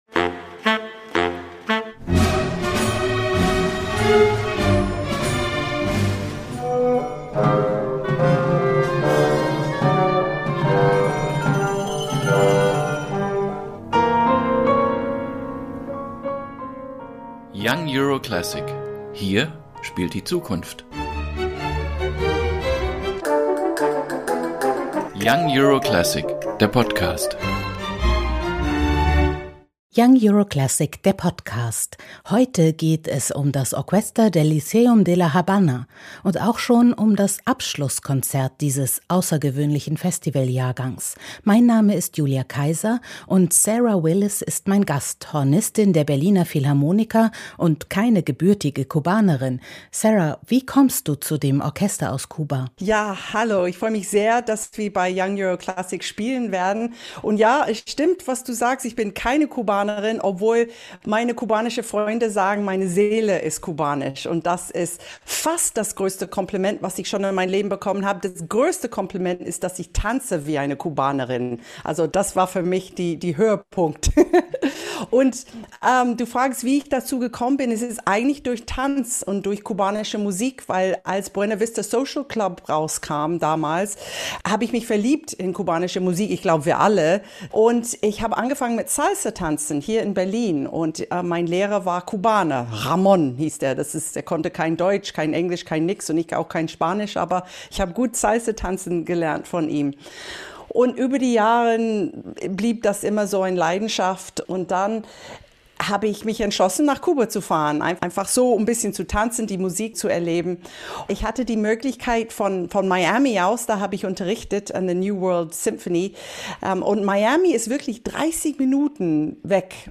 Konzerteinführung 15.08.21 | Hornistin Sarah Willis über ihre Leidenschaft Salsa und den Stellenwert der klassischen Musik in Kuba, wie sie zum Orquesta del Lyceum de La Habana fand und wie es war, ein Jahr lang nicht mit ihnen aufzutreten – und über ihren Fonds „Instruments for Cuba”, der die kuban...